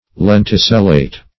Search Result for " lenticellate" : The Collaborative International Dictionary of English v.0.48: Lenticellate \Len`ti*cel"late\ (l[e^]n`t[i^]*s[e^]l"l[asl]t), a. (Bot.) Producing lenticels; dotted with lenticels.
lenticellate.mp3